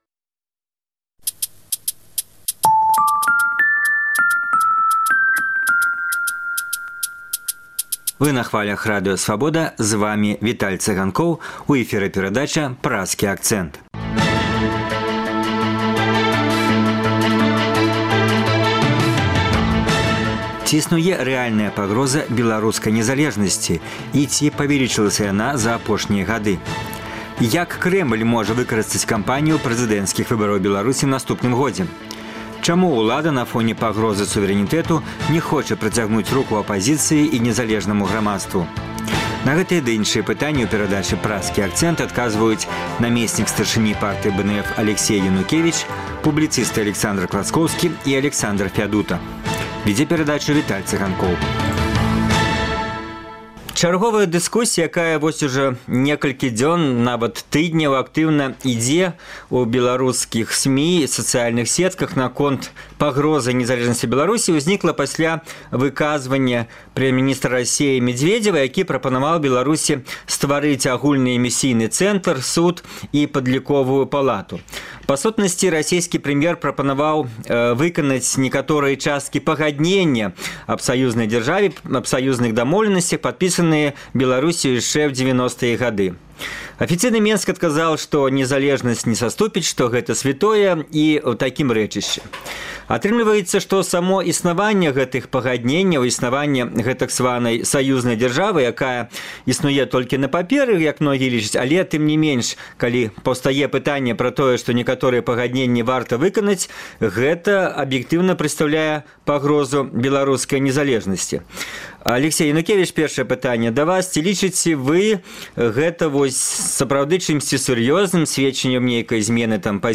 На гэтыя ды іншыя пытаньні ў перадачы Праскі Акцэнт адказваюць
Вядзе дыскусію